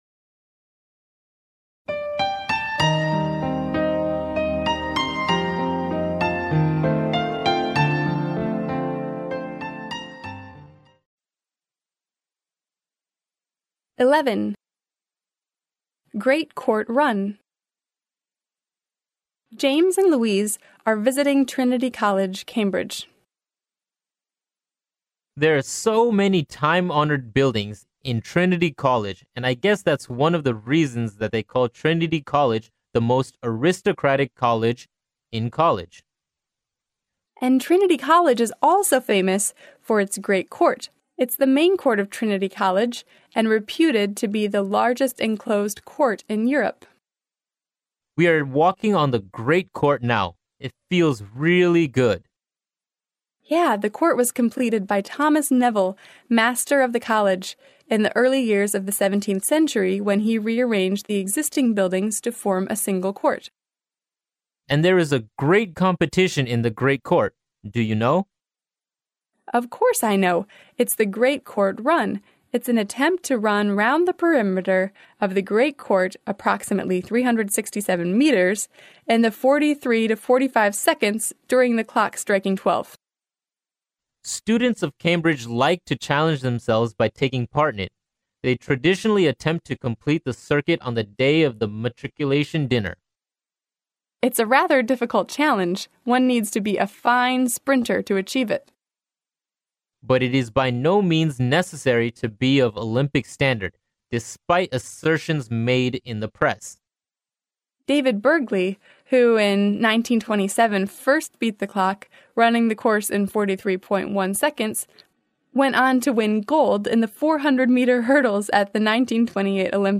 剑桥大学校园英语情景对话11：庭院中的赛跑（mp3+中英）